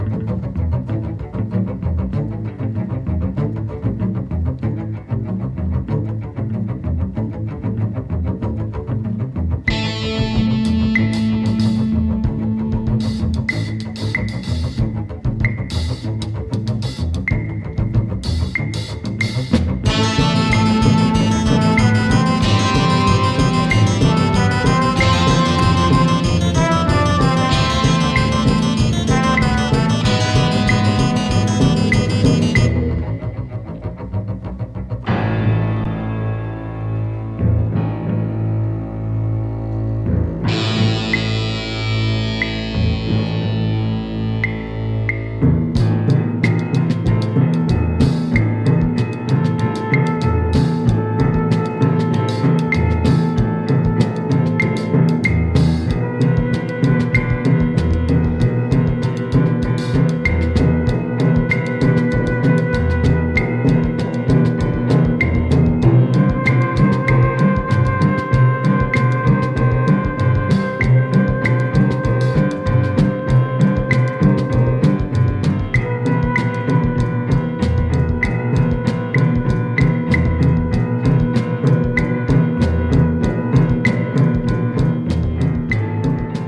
jazz, soul, library music.